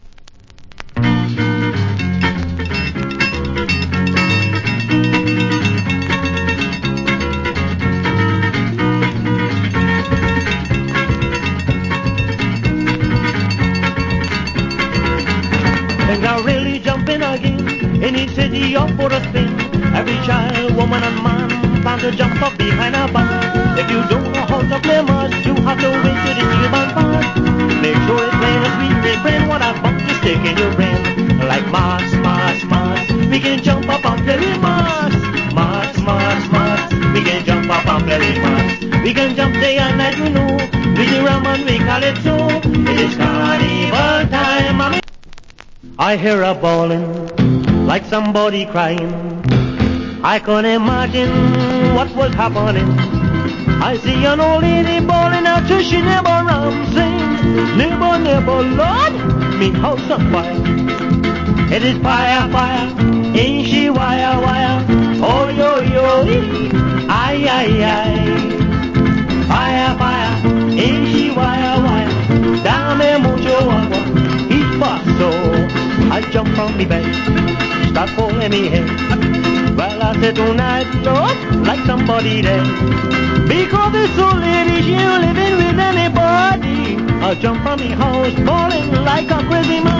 Wicked Calypso Vocal.